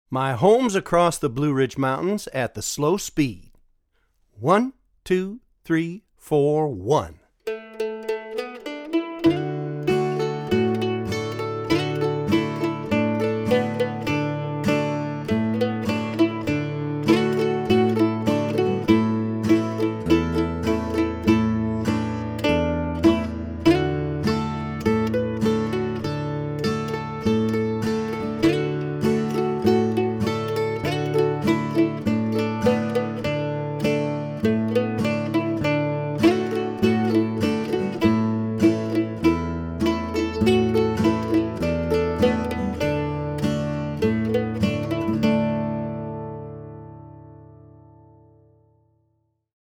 DIGITAL SHEET MUSIC - MANDOLIN SOLO
Traditional Mandolin Solo
(both slow and regular speed)